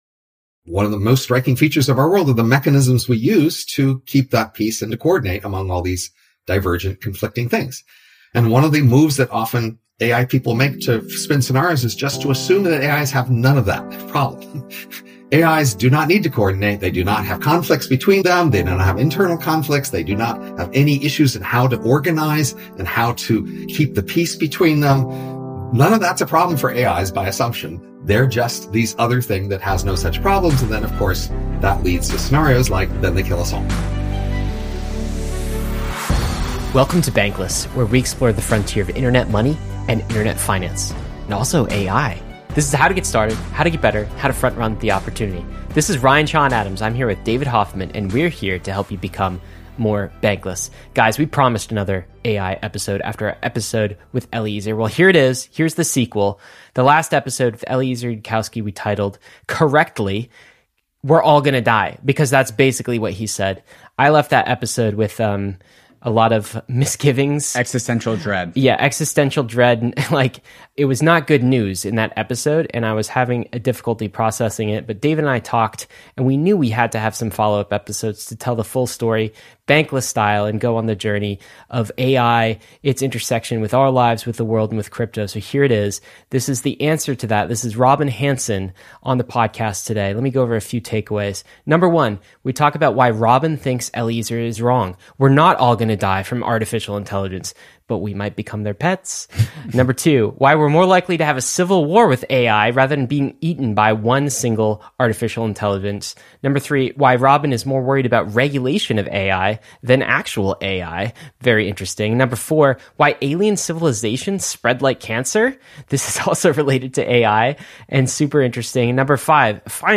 We’re NOT Going to Die with Robin Hanson Apr 17, 2023 auto_awesome In this engaging conversation, Robin Hanson, a Professor of Economics at George Mason University, challenges dire predictions about AI domination. He argues that rather than being enslaved, humans might be treated as pets by evolved AIs.